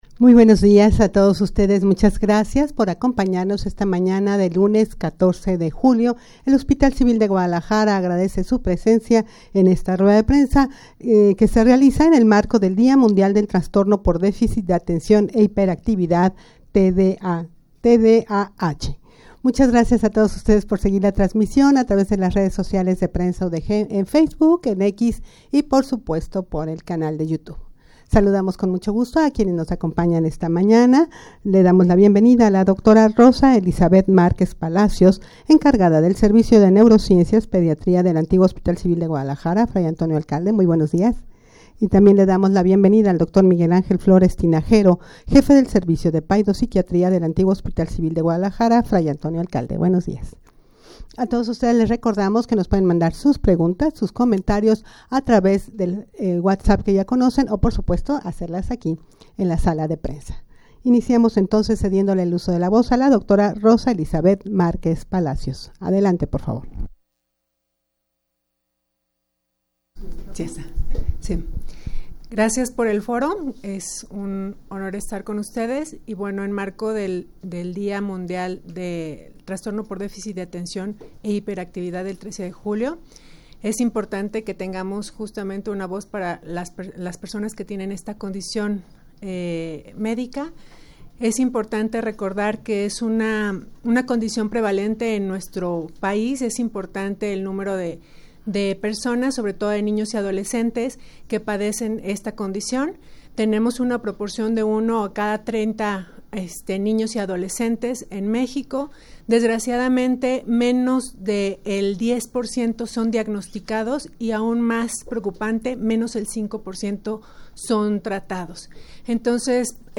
rueda-de-prensa-en-el-marco-del-dia-mundial-del-trastorno-por-deficit-de-atencion-e-hiperactividad-tdah.mp3